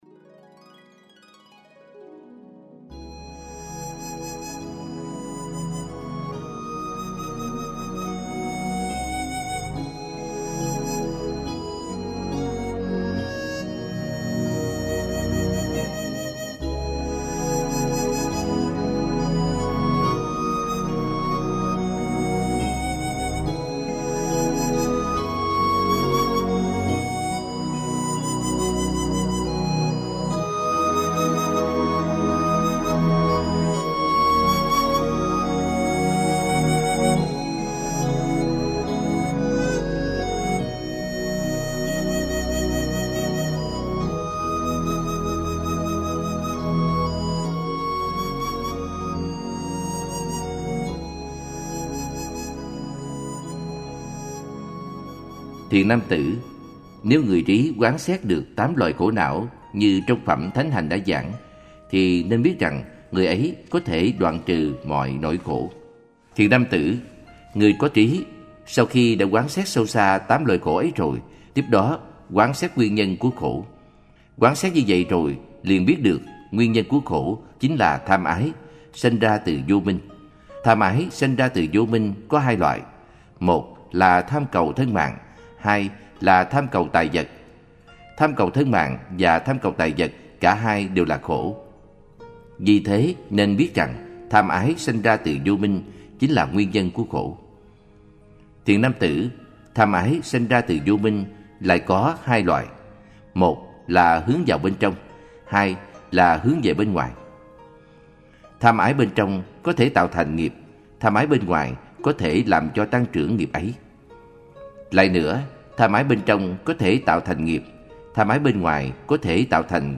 Giảng giải